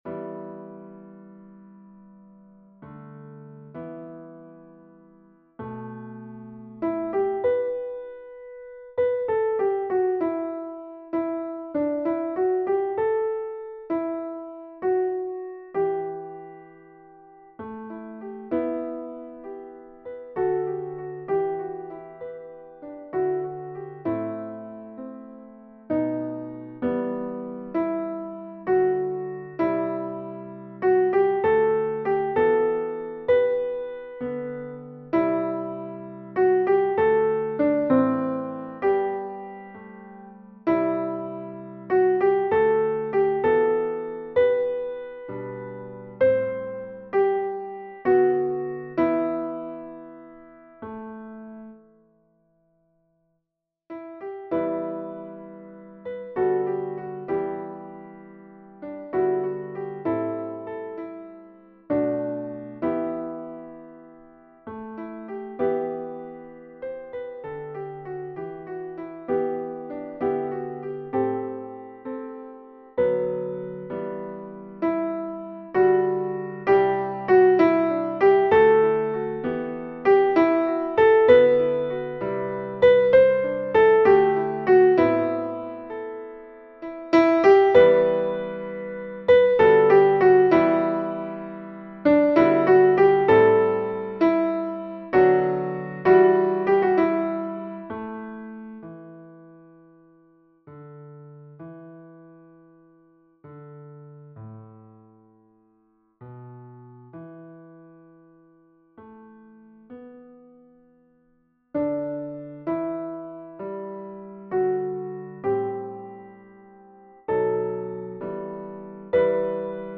Versions "piano"
Mezzo Soprano